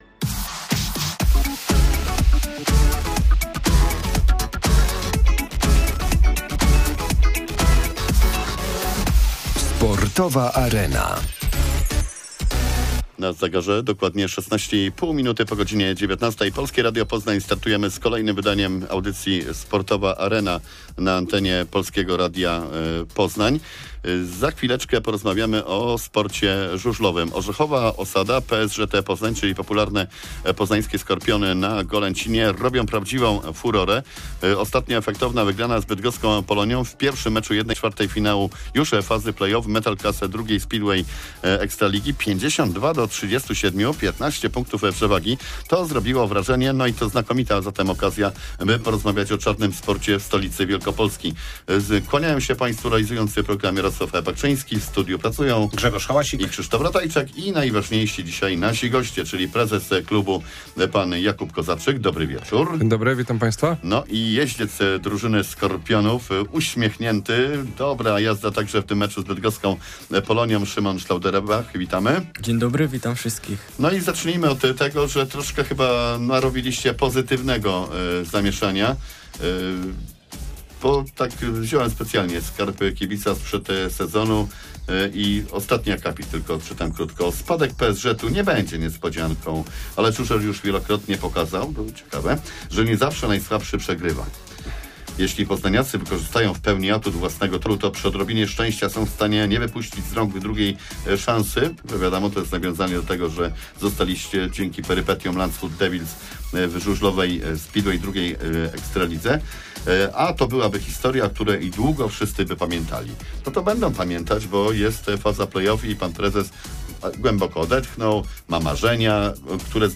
Sportowa Arena live - wtorek 20 sierpnia godz. 19.15. Goście z Poznańskiego Stowarzyszenia Żużla